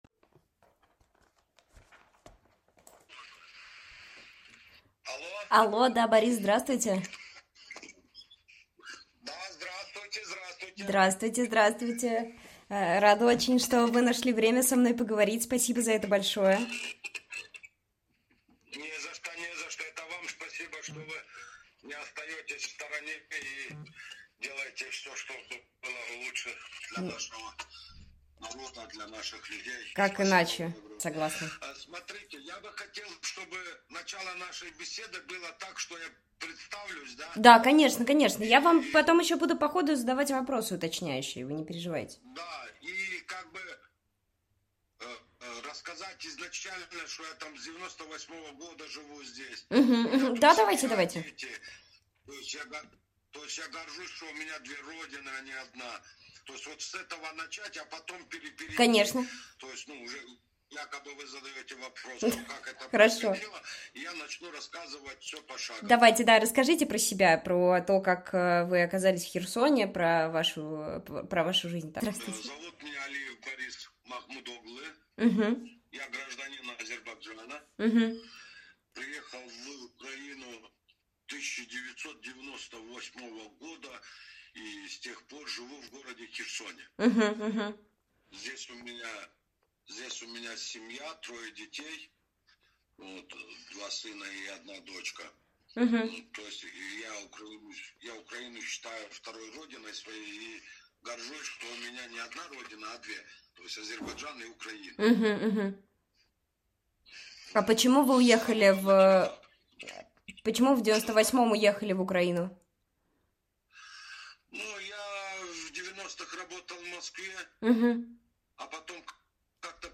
Интервью Предыдущая Следующая Часть 1